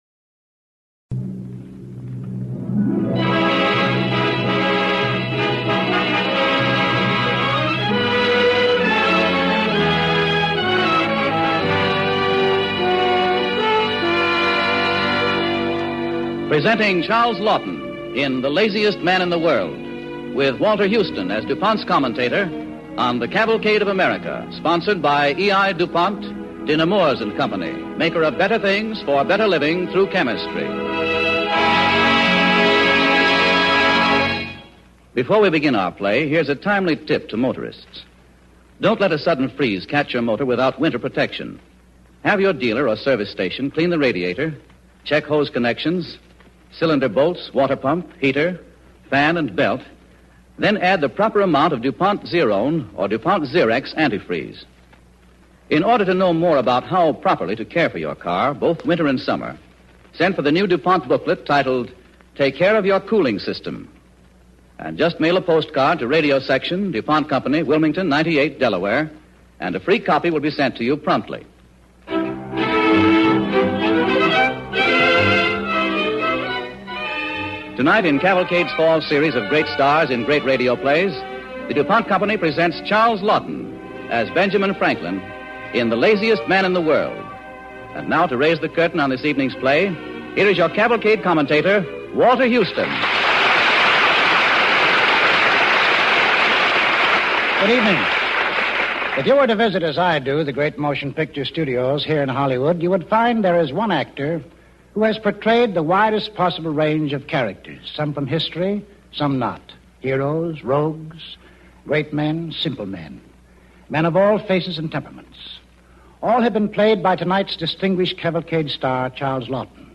starring Charles Laughton
with host Walter Houston